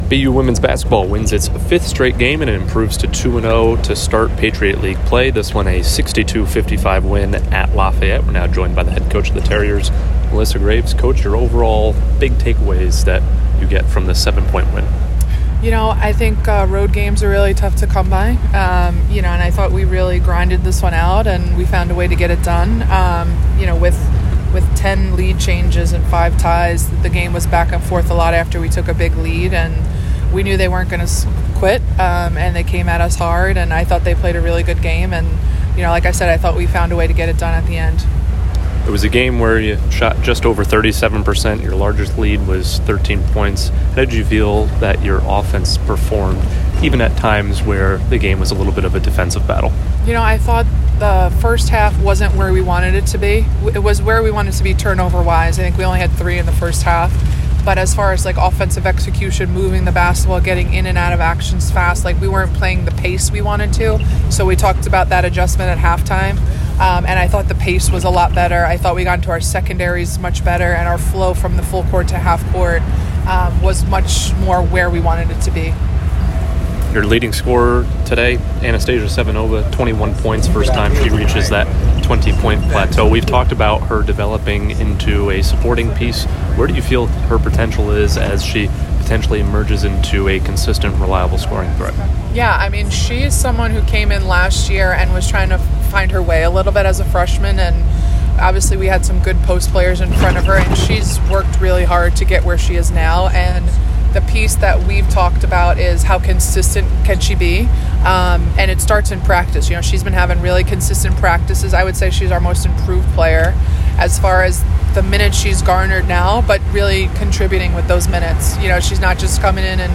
WBB_Lafayette_1_Postgame.mp3